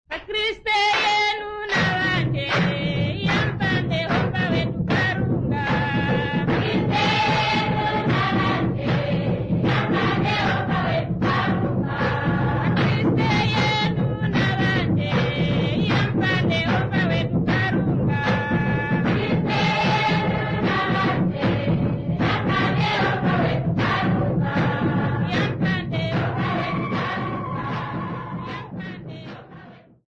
Sambiu church music workshop participants
Choral music Namibia
Ngoma (Drum) Namibia
Africa Namibia Sambiu mission, Okavango sx
field recordings
Church song with drum and clapping accompaniment.